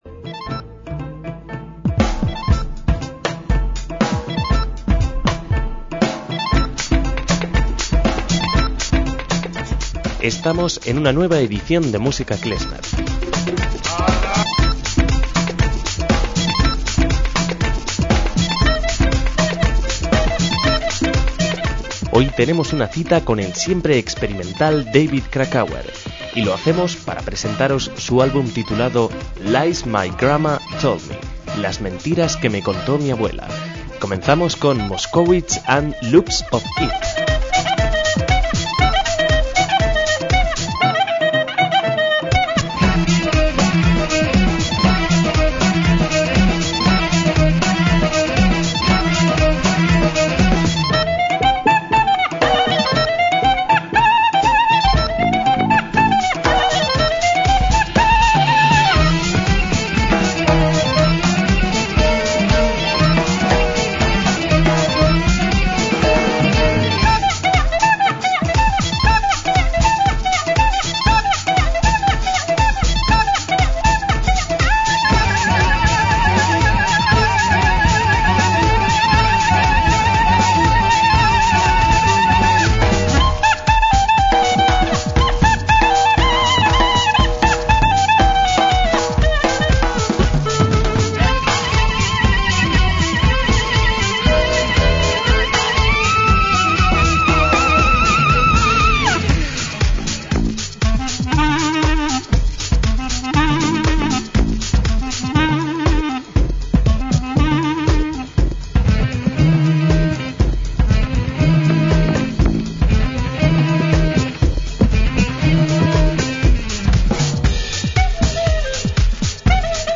MÚSICA KLEZMER
clarinetista